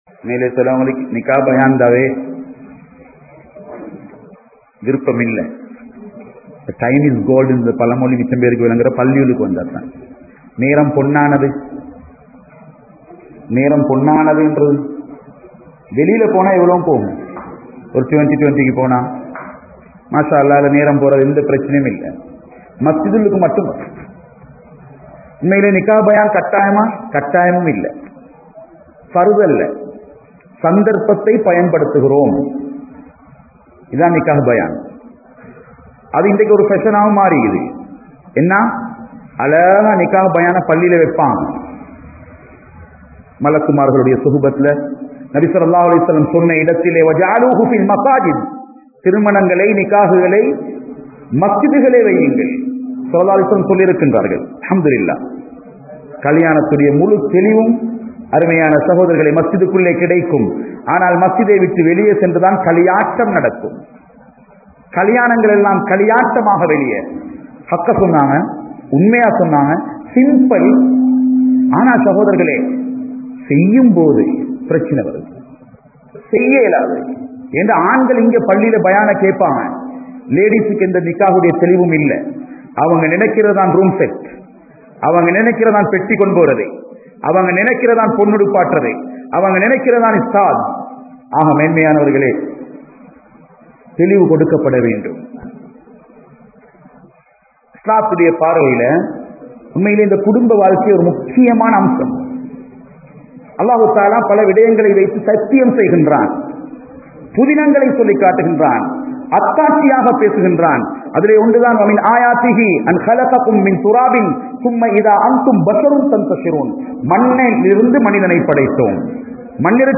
Indraya Thirumanagalum Aadamparangalum (இன்றைய திருமணங்களும் ஆடம்பரங்ளும்) | Audio Bayans | All Ceylon Muslim Youth Community | Addalaichenai